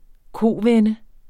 Udtale [ ˈkoˀˌvεnə ]